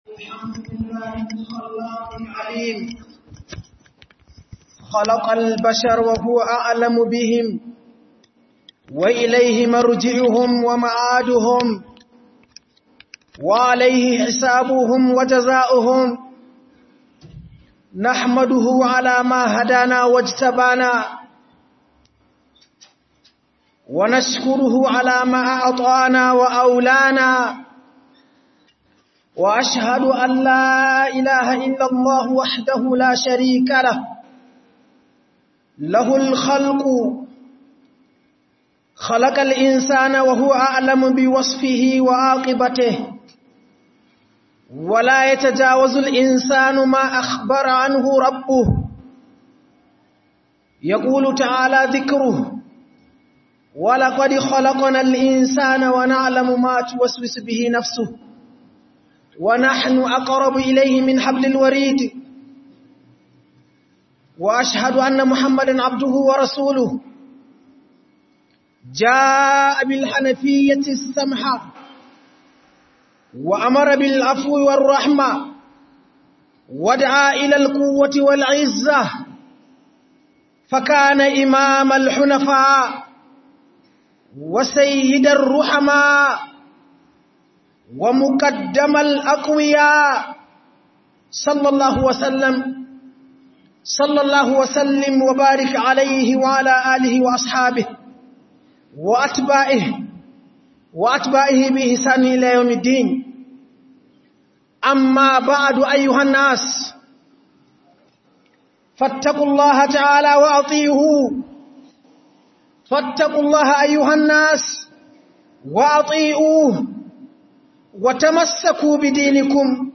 Muyi Imani Sai Allah ya Taimakemu - HUDUBA